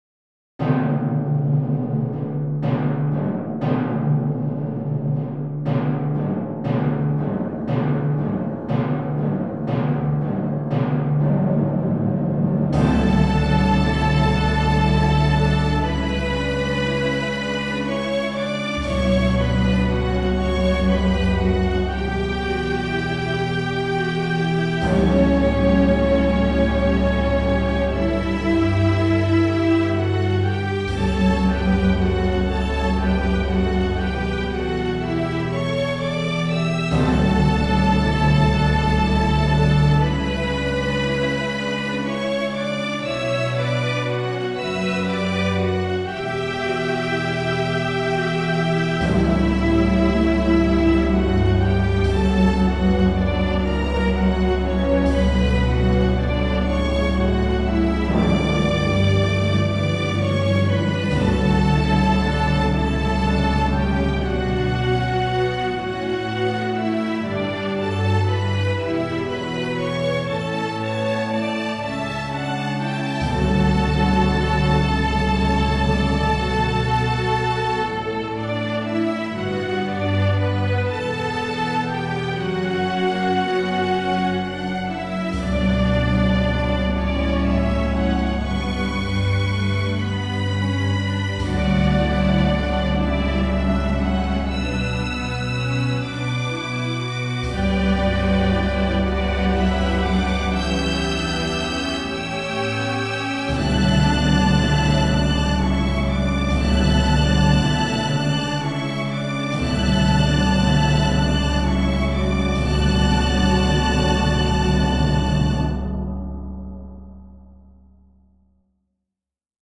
the entrance of the bride and groom, which is about two minutes long.
Customised wedding music; can't beat it.